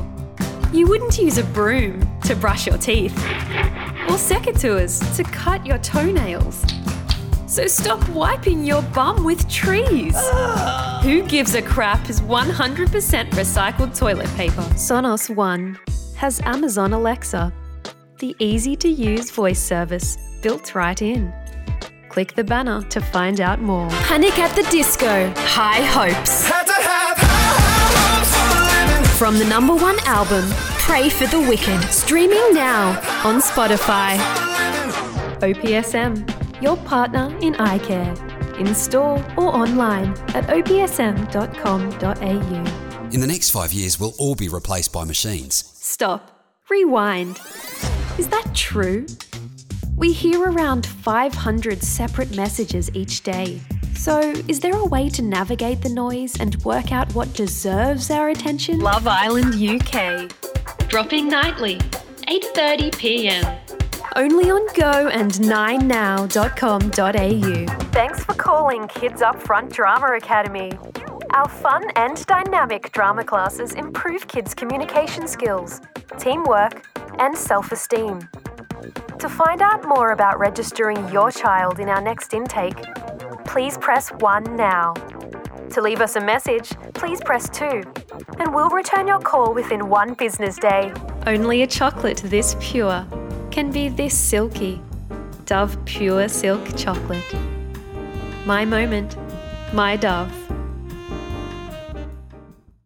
Demo
Child, Teenager, Young Adult
My style is young-but-wise. I use my youthful edge to specialise in tween, teen, and young adult content. Common descriptions of my vocal style have been educative, inviting, and colourful. I have curated a voice reel that is playful but elegant.
australian | natural
cool
quirky